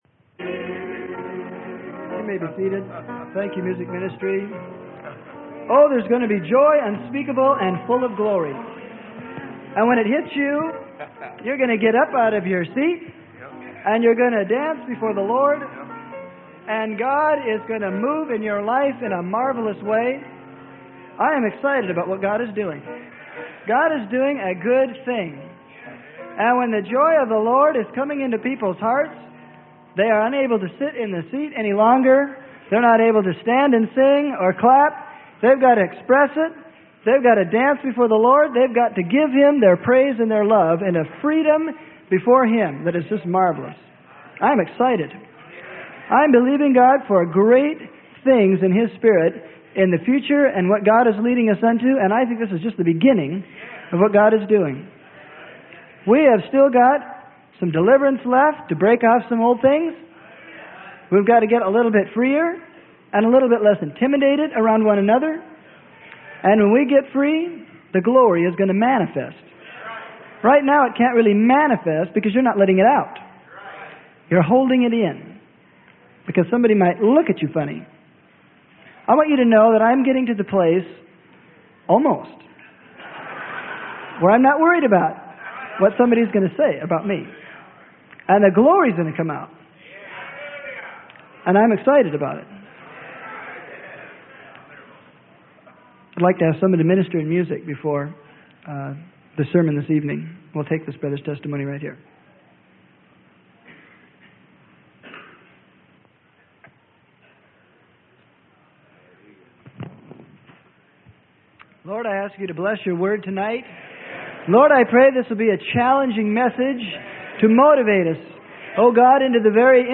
Sermon: Solomon'S Temple: A Type Of What God Desires To Do In You.